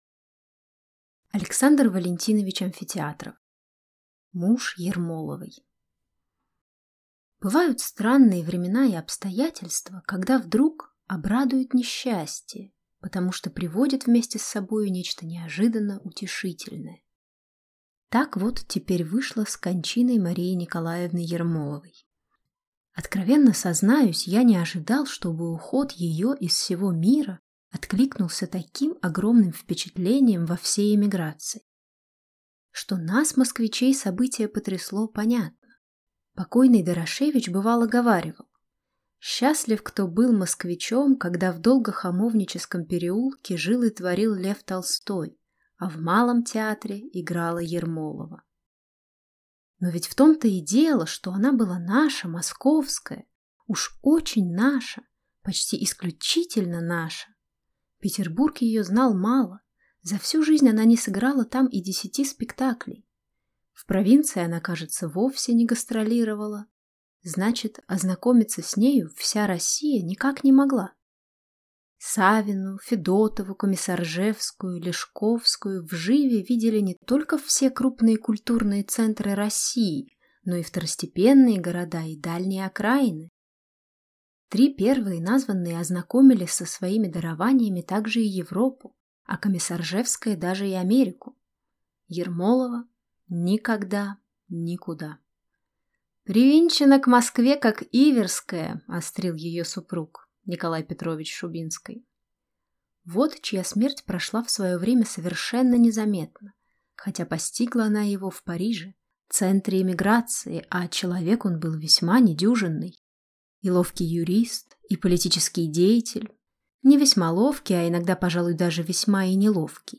Аудиокнига Муж Ермоловой | Библиотека аудиокниг